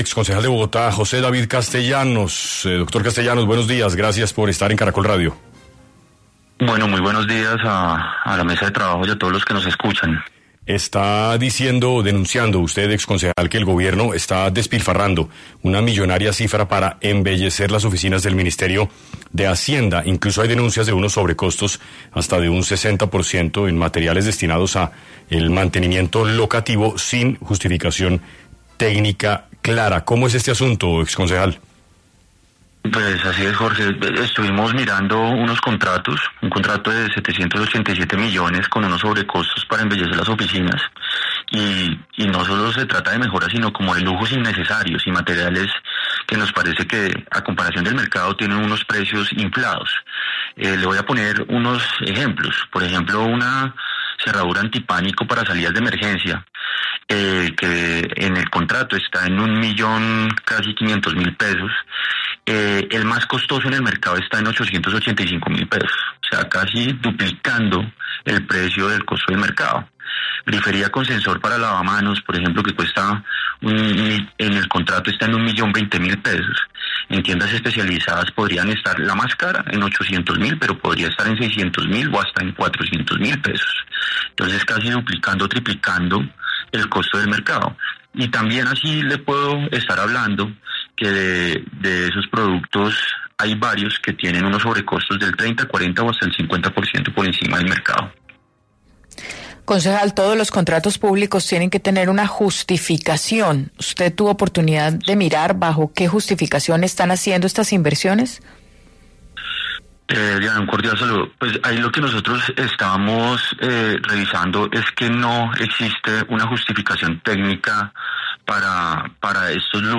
¿Sobrecostos? El exconcejal, José Castellanos habló en 6AM sobre cuál sería la inversión que el Gobierno estaría utilizando para “embellecer” las oficinas del Ministerio de Hacienda